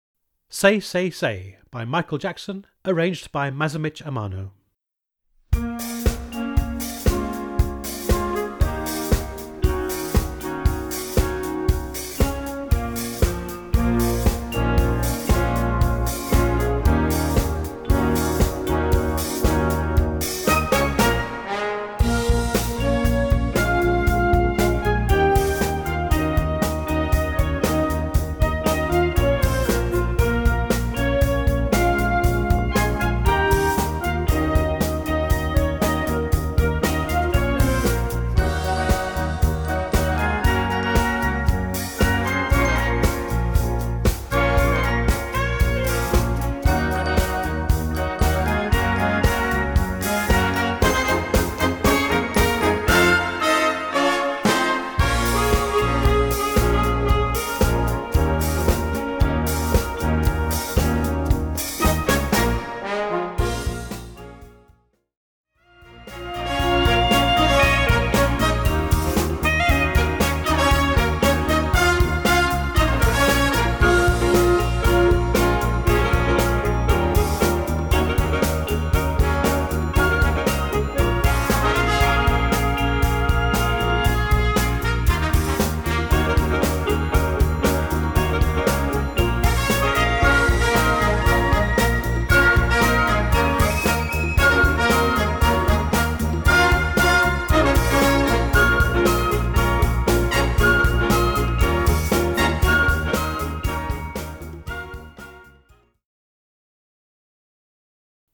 POP & ROCK TUNES
Concert Band
for concert band